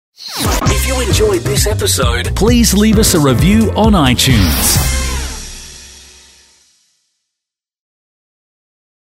Voice Overs